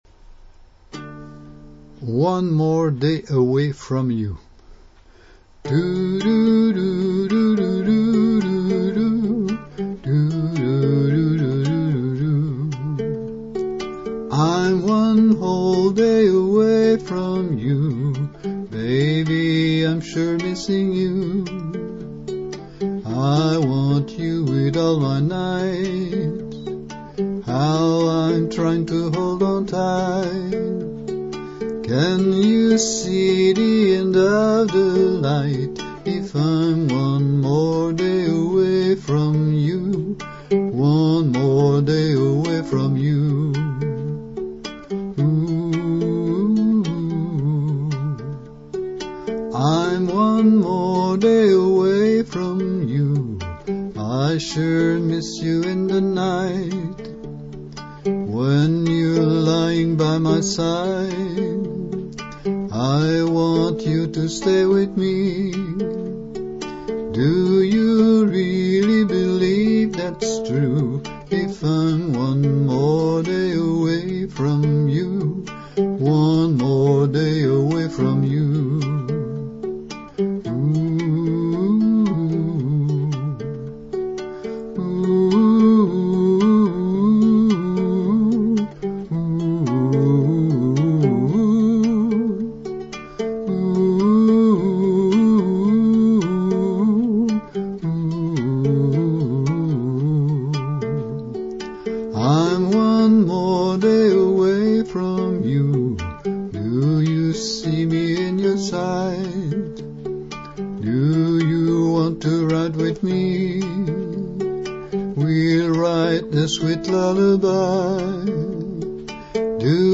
onemoredayawayfromyouuke.mp3